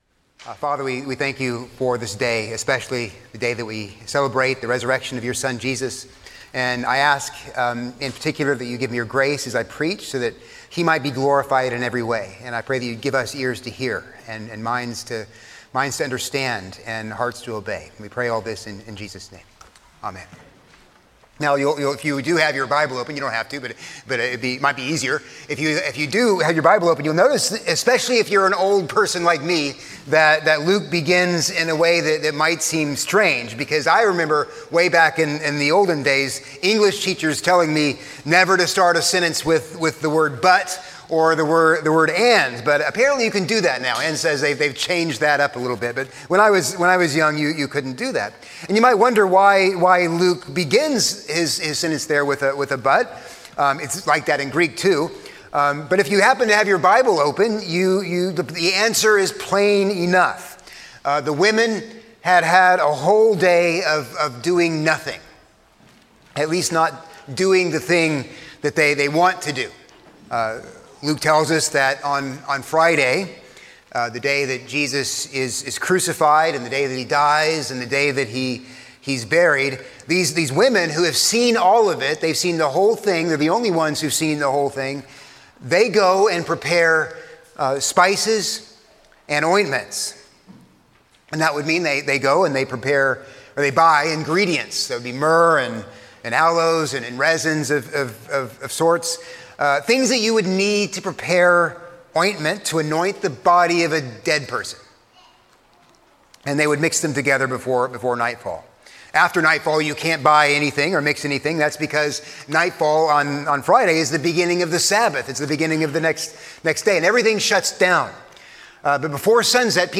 A sermon on Luke 24:1-12